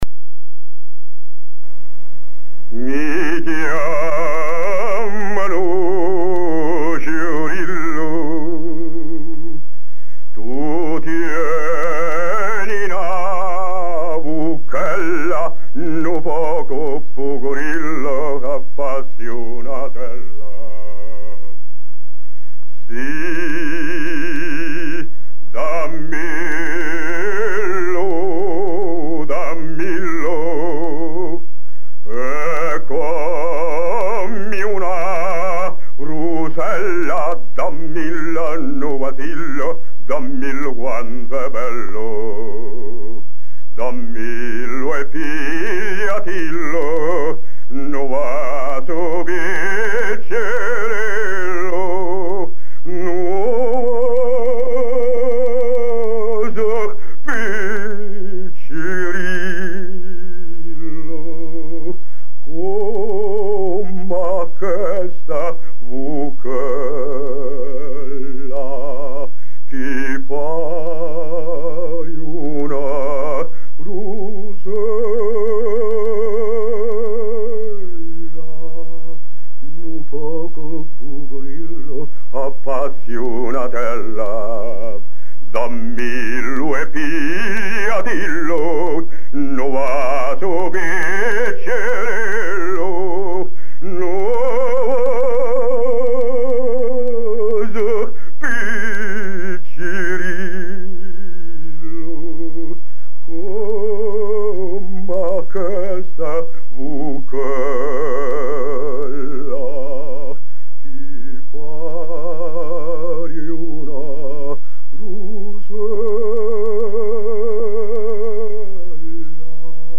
Between 1940 and 1946, the Library of Congress and the University of Wisconsin cosponsored a field survey of Wisconsin folk music.
The performances were recorded directly to shellac discs and later transferred to tape.